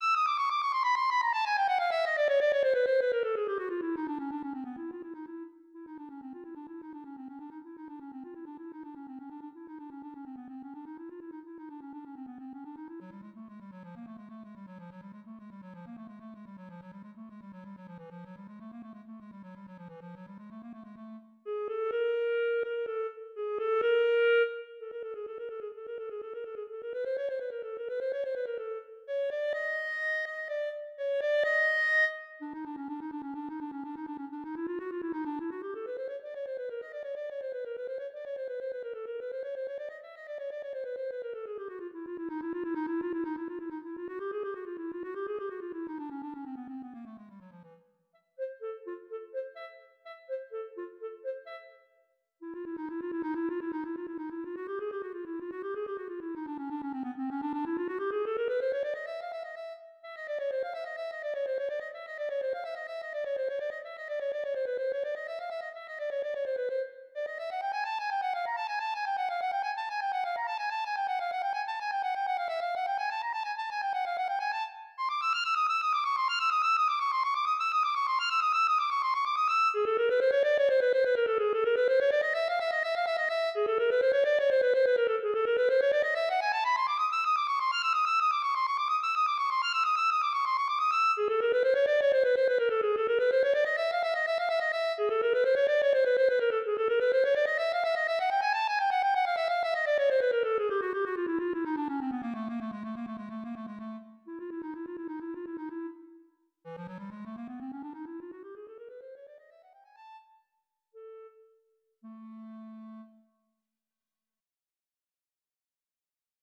Voicing: Clarinet